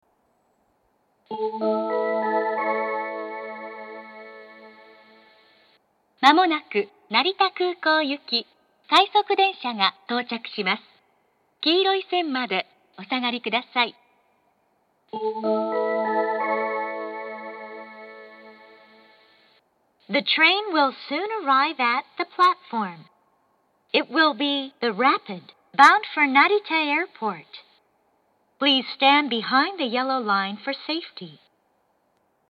音質が向上しています。
下り接近放送 快速成田空港行の放送です。